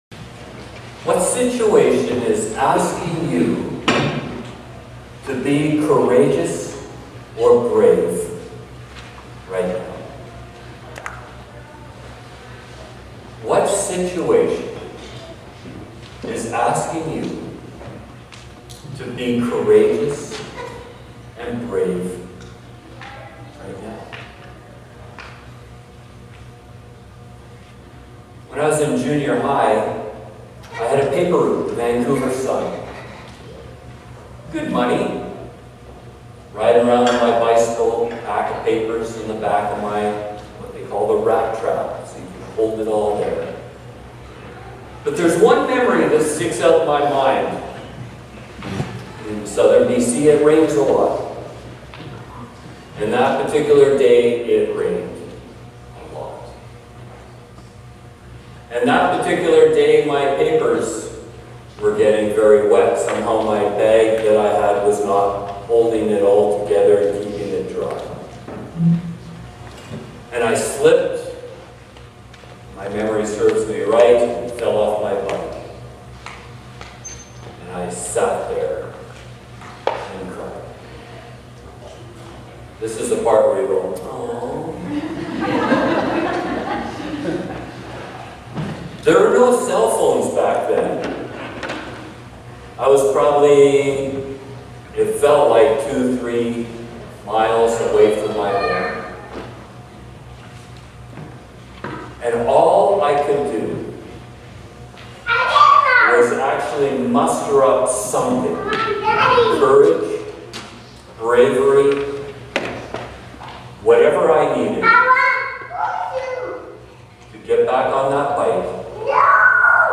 Sermons | Rosenort Community Church